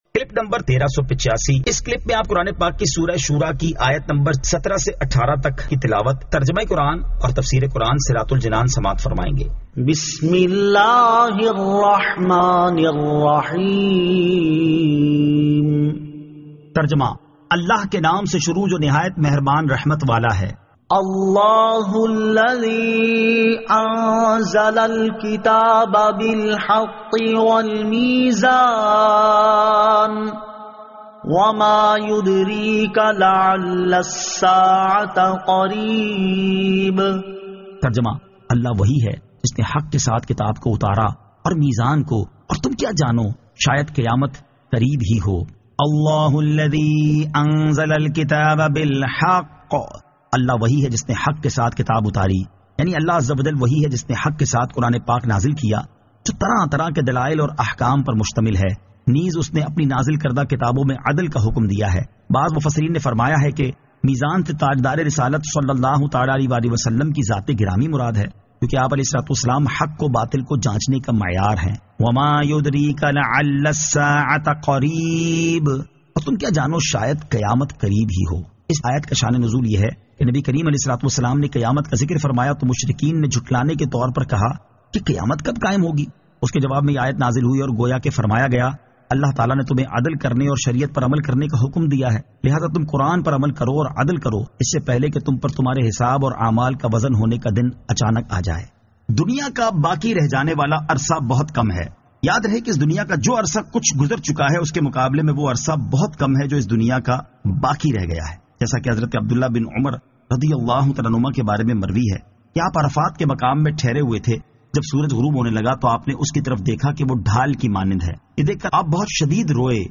Surah Ash-Shuraa 17 To 18 Tilawat , Tarjama , Tafseer